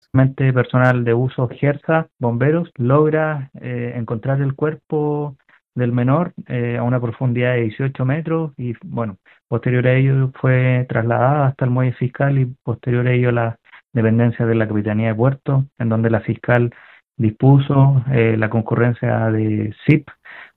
Así lo explicó el capitán de Puerto Lago Villarrica, Alex Geldes.